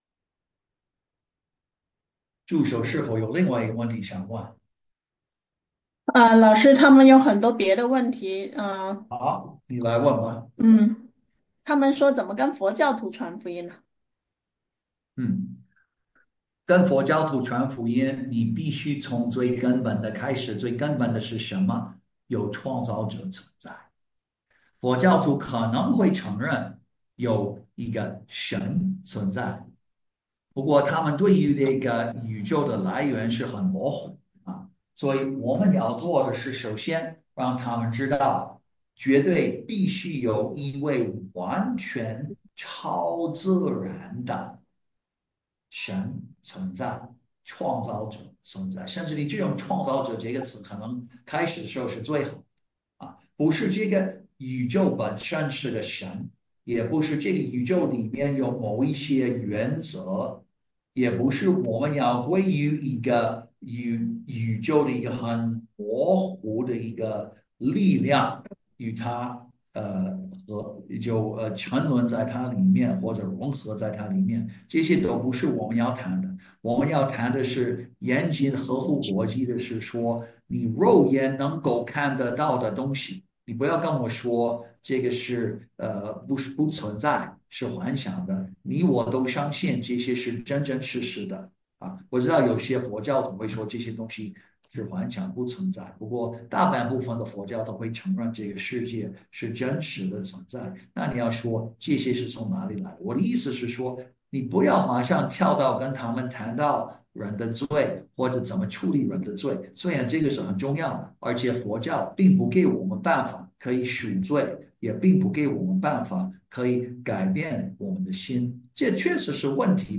本次讲座将手把手教你从引出话题开始，解答常见的福音疑惑，透过 3 个步骤使用神给我们的证据，帮助朋友看到福音是真实的。